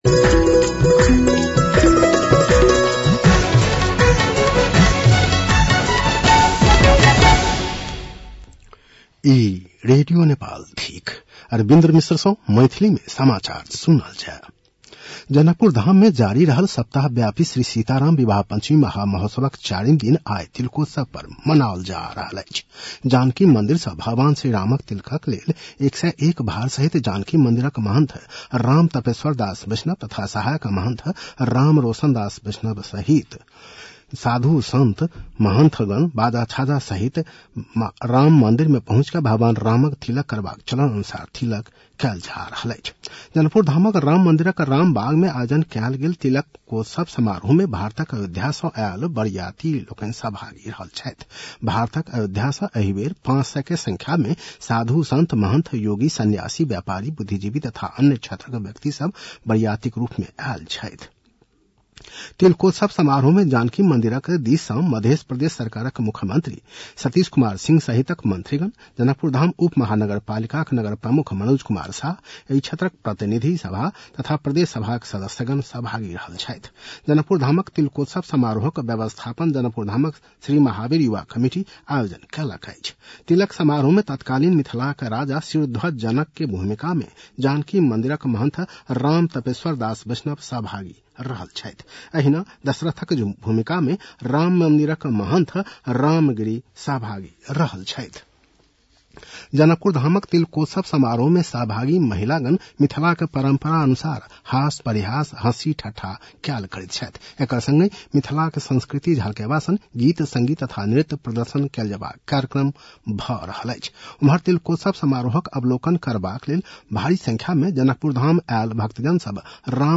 मैथिली भाषामा समाचार : २० मंसिर , २०८१